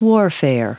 Prononciation du mot warfare en anglais (fichier audio)
Prononciation du mot : warfare